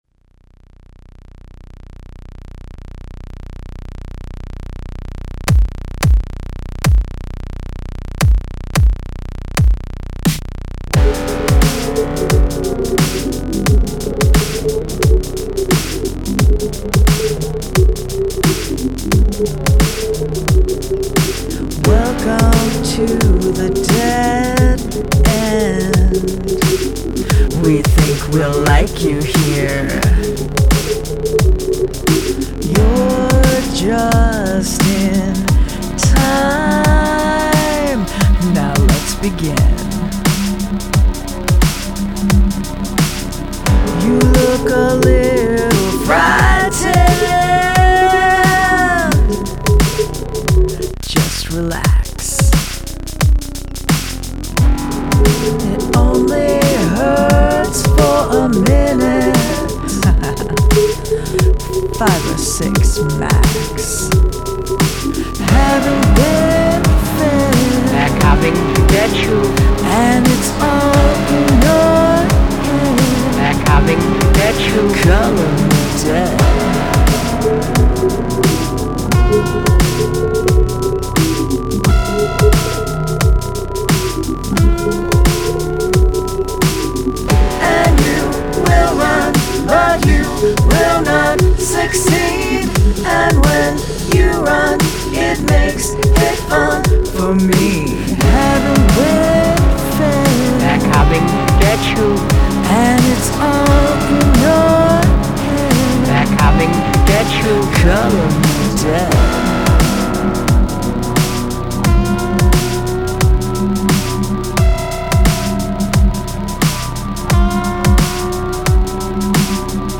Sampled Vocal Hook
total genre shift here! It's like a gothy trip-hop thing?
The harmonies sound really good when they kick in.